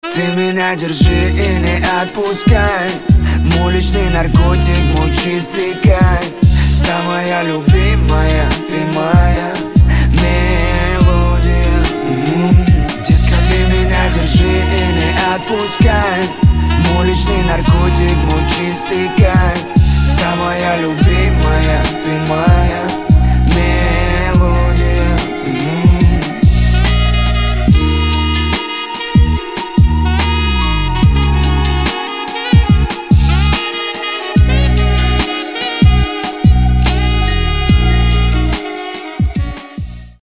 • Качество: 320, Stereo
лирика
Хип-хоп
русский рэп
мелодичные
красивая мелодия
Саксофон